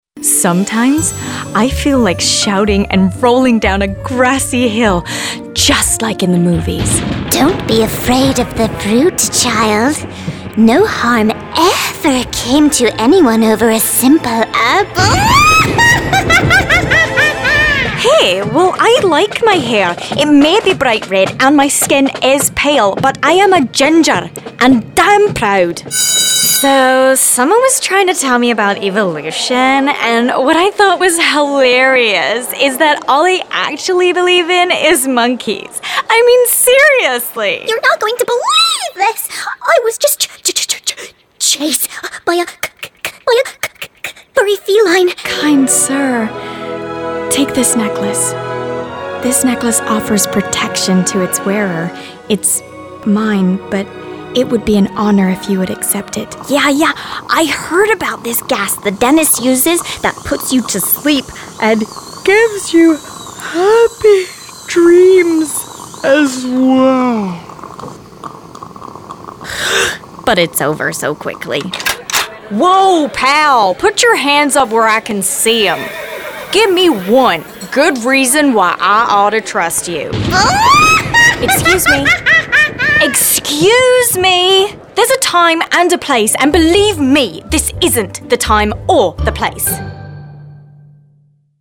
Voice Over Demos
Animation (Various)